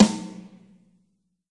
Drum Loops » Dynamic Drums Loop
标签： StudioDrumKit Drums Drum StudioDrumSet
声道立体声